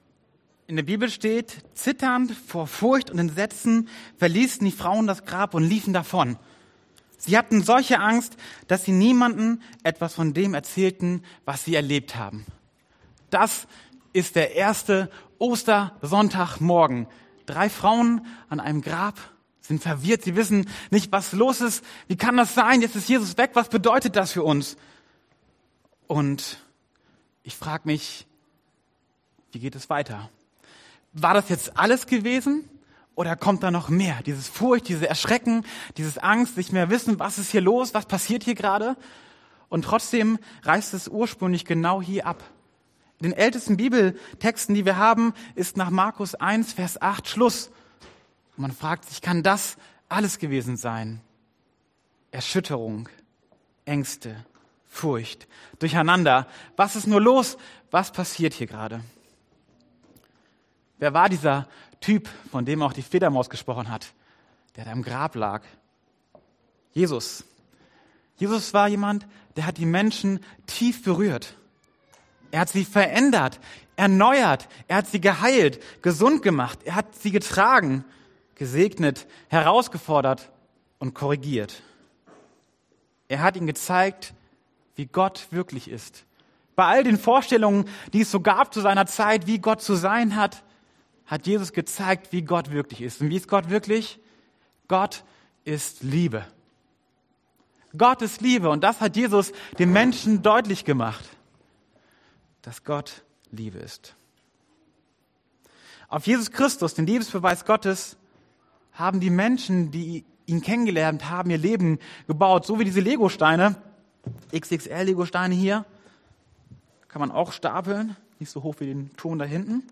TROTZ Erschütterungen FEST im Glauben Passage: Markus 16,1-8; 1. Korinther 15,17-20 Dienstart: Predigt Themen: Zweifel « Sorgen SEGEN – mehr als ein frommer Wunsch?